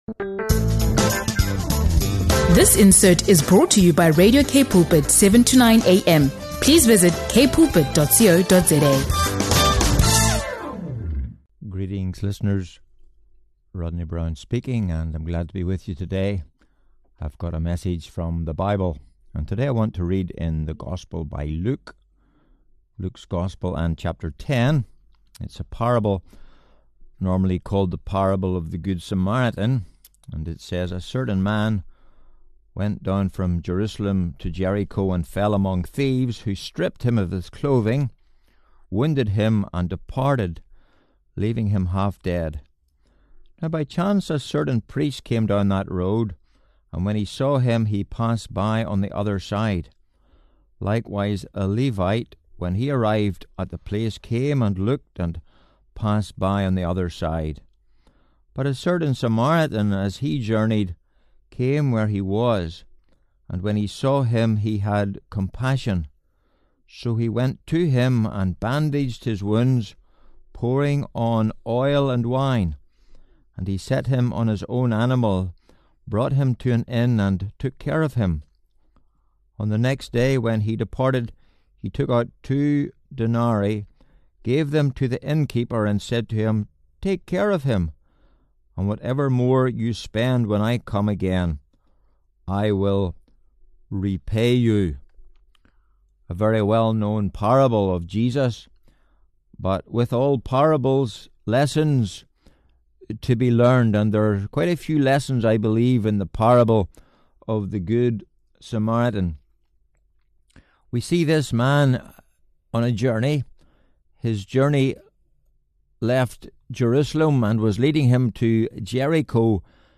In this Sunday Devotion